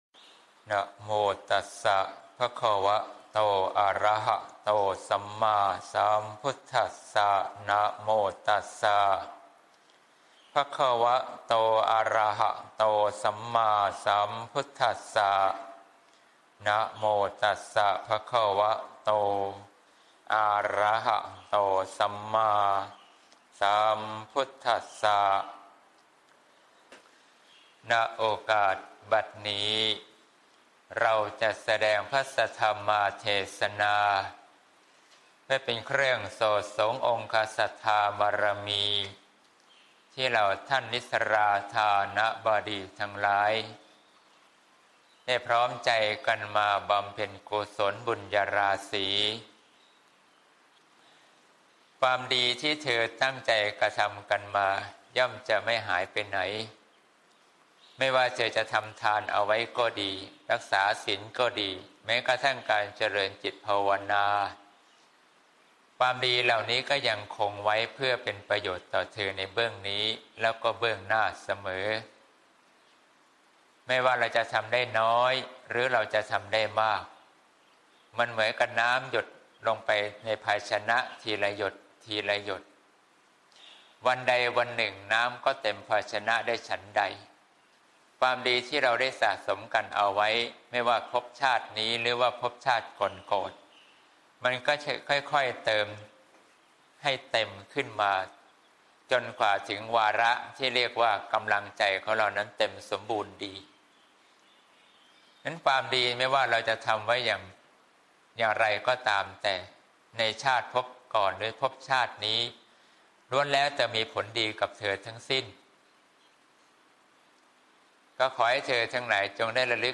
เทศน์ (เสียงธรรม ๓๐ มี.ค. ๖๘)